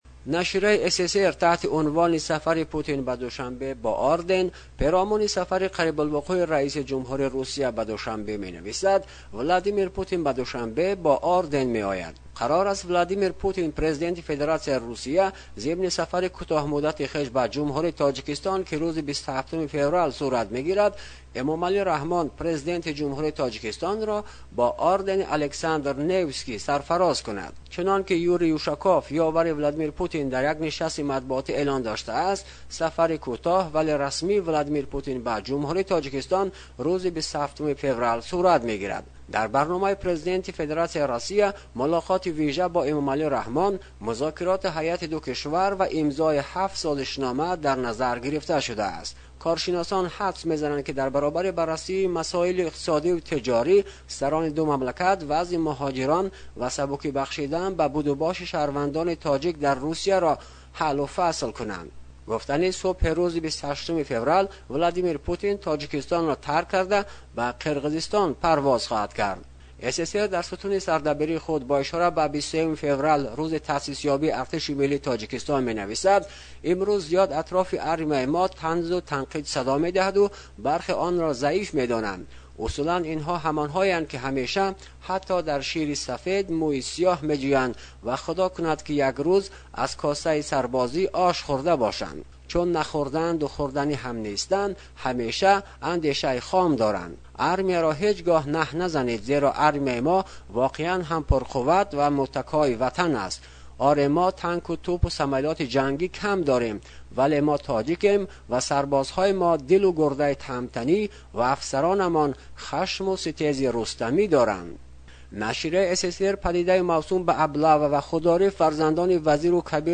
Гузориши